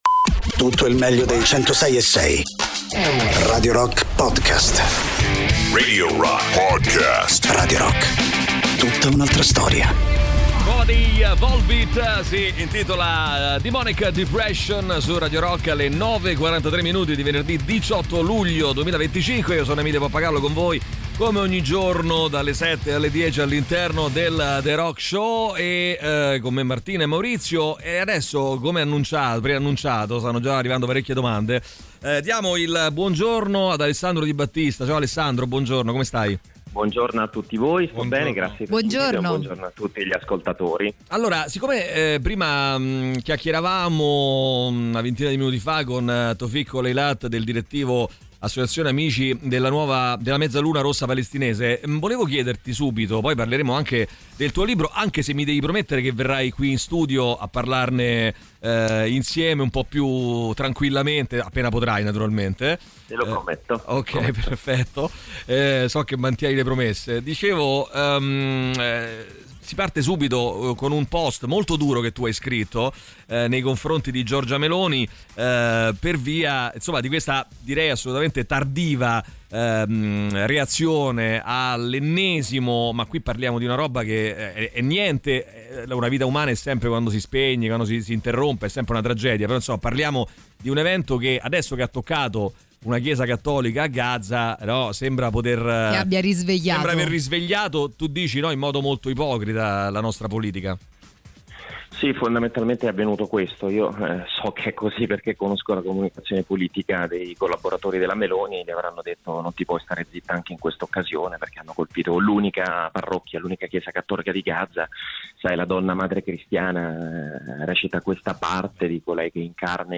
Radio Rock FM 106.6 Interviste